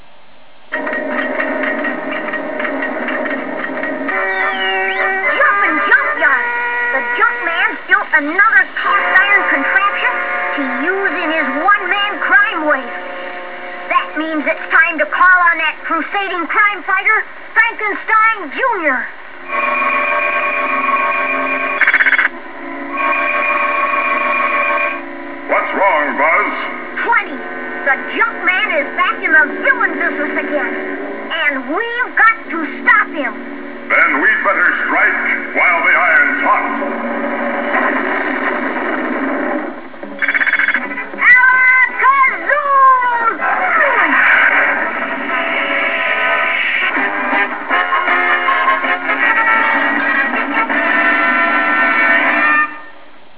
Together they go around fighting crime, but mostly the robot is just saving the little boy. Click to hear an audio from the show.